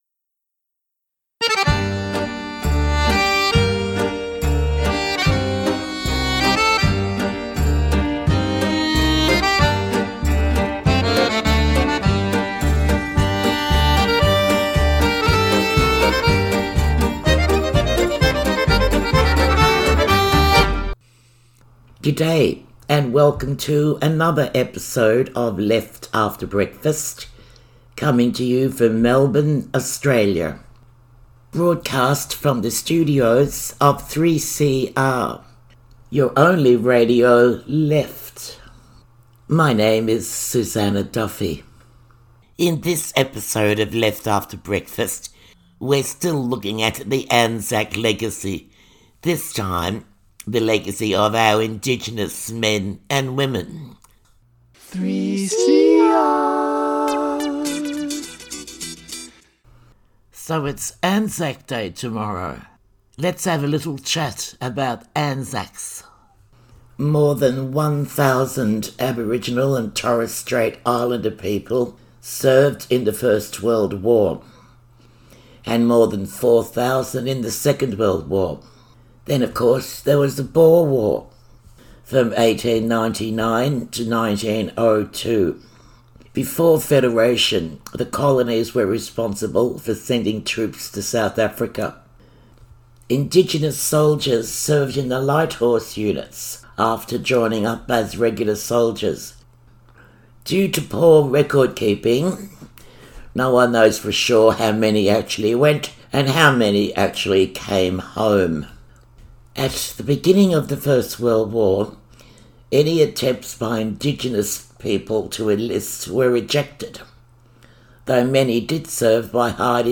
country music
sweet voice